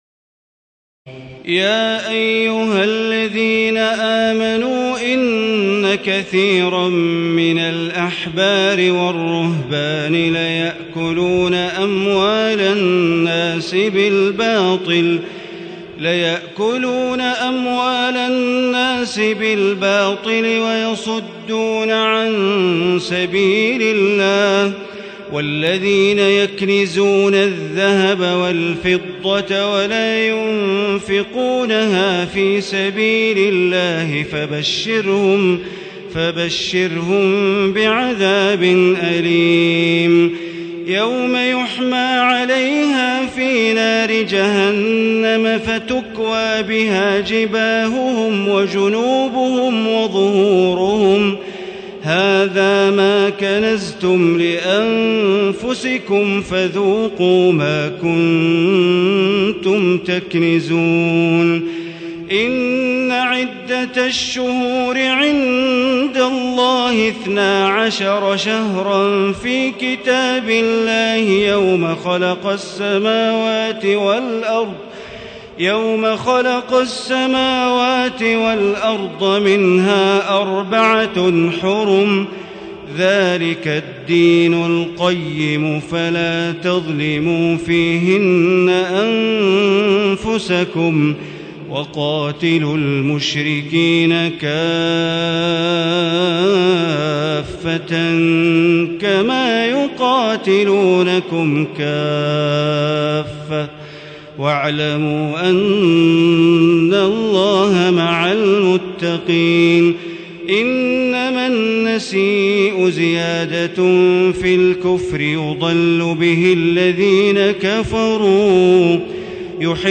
تراويح الليلة التاسعة رمضان 1437هـ من سورة التوبة (34-96) Taraweeh 9 st night Ramadan 1437H from Surah At-Tawba > تراويح الحرم المكي عام 1437 🕋 > التراويح - تلاوات الحرمين